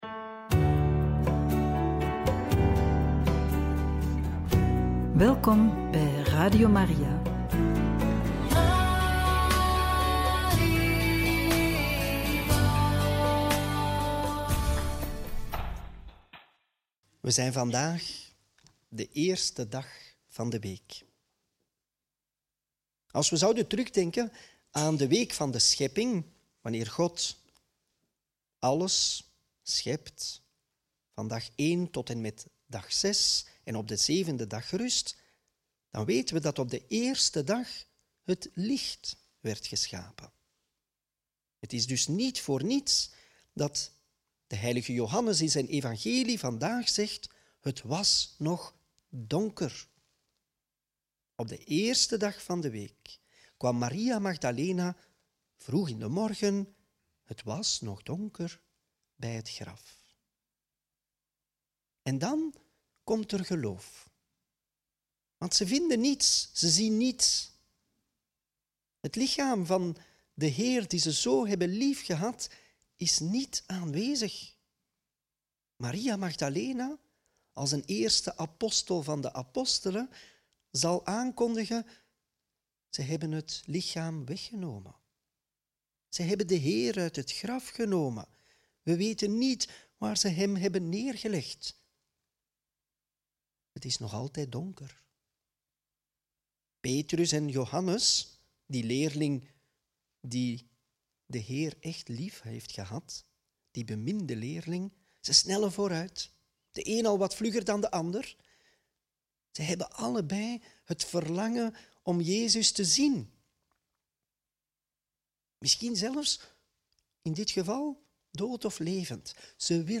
Homilie op Paasdag - Joh 20, 1-9